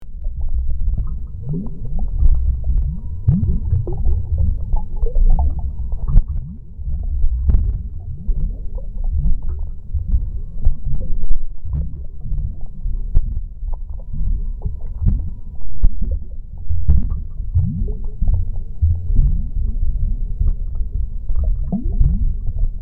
Gaia Ambient Underwater Sound Effect.mp3